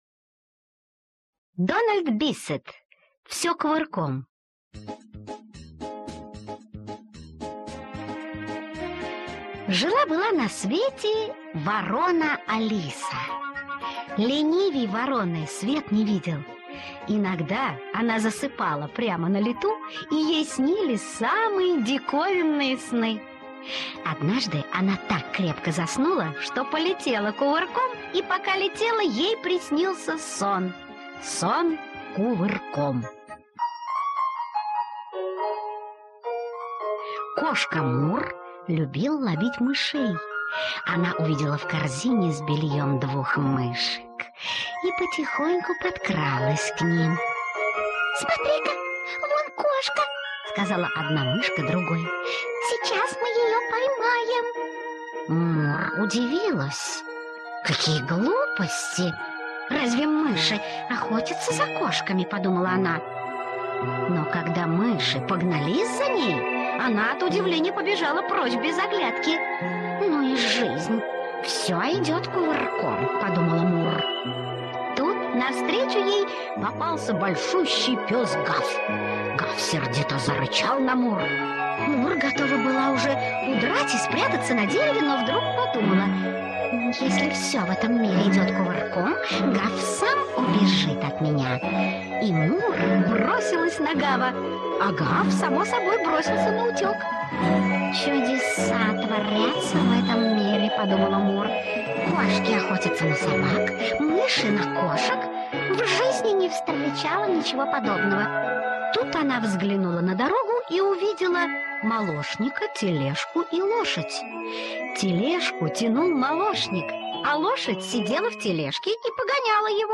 Аудиосказка «Все кувырком»